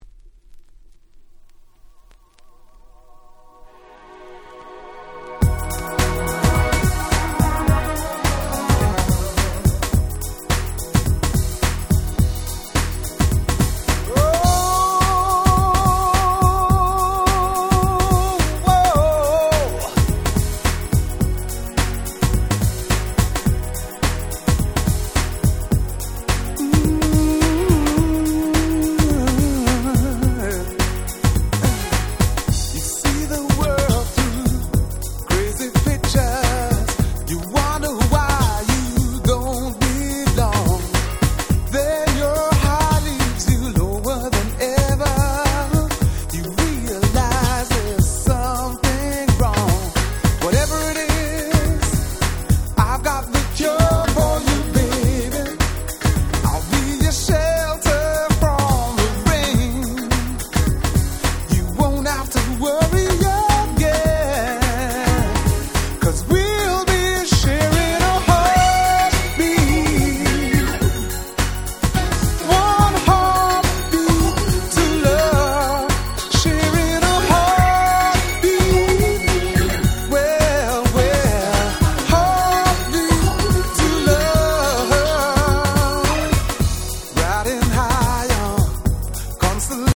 92' Nice UK R&B LP !!